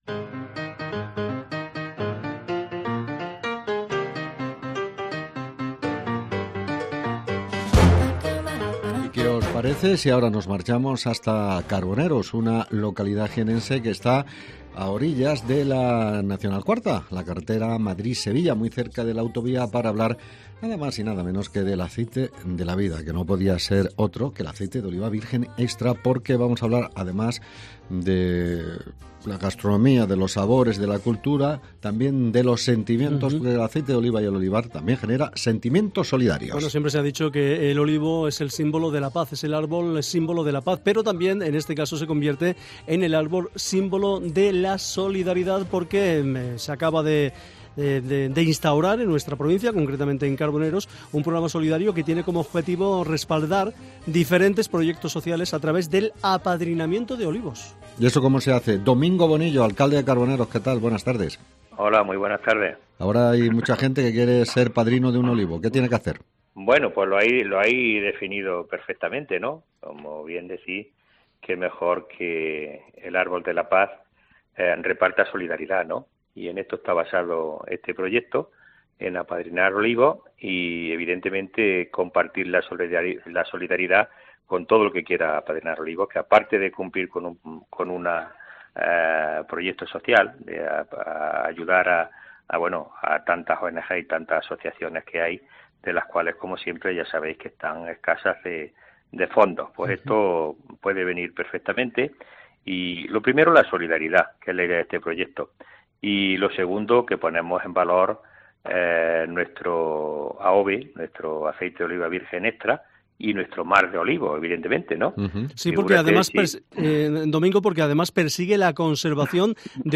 En Oliva 360º hemos charlado con Domingo Bonillo, alcalde de Carboneros, sobre "El aceite de la vida"